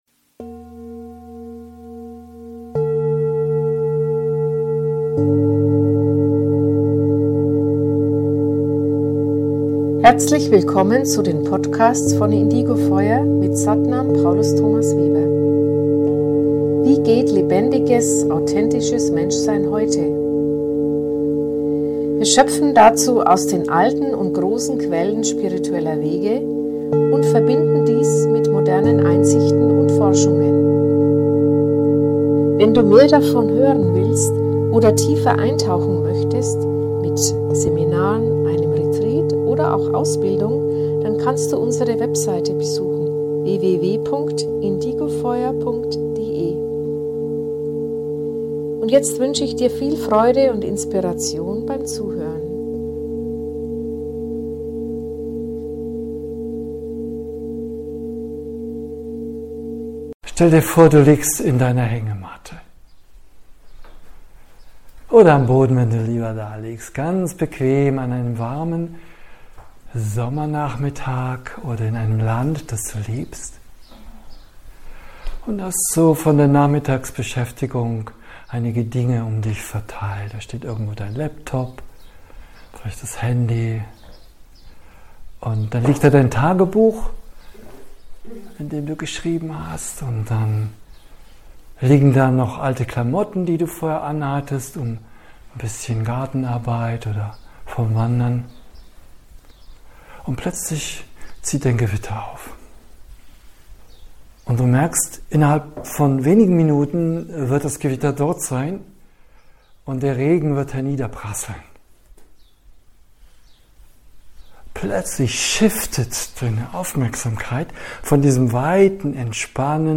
Dieses Teaching ist ein Live-Mitschnitt aus einem Yoga-Ausbildungs-Zentrum (Wünricht) und bietet 7 Elemente dazu an.